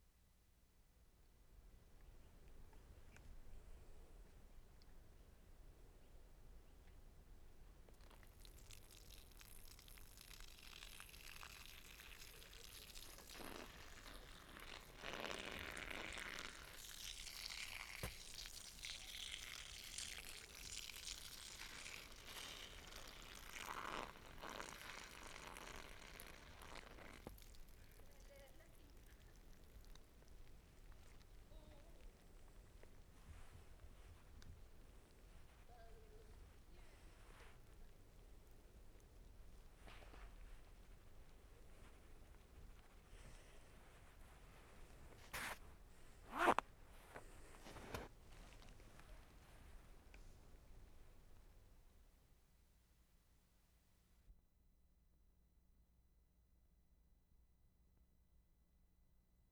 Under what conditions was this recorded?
Cembra, Italy March 28/75